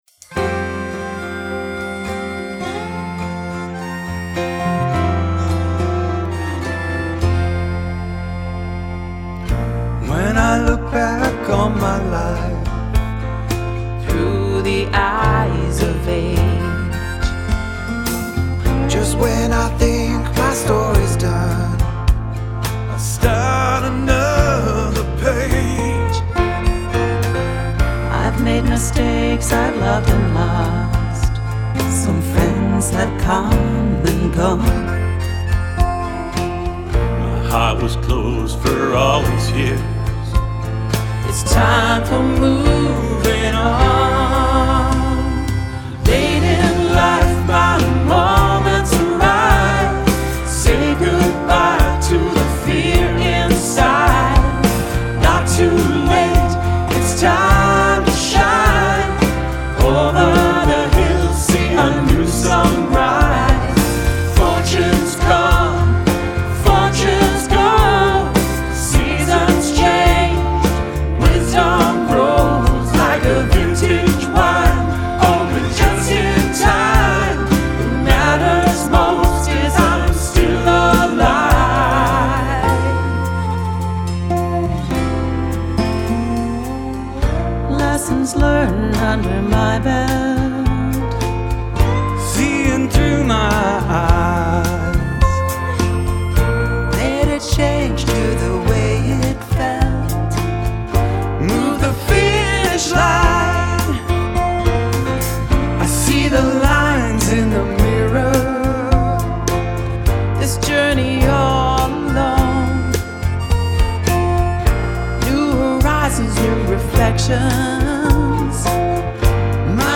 A professional drummer came in to bang out some beats
Drums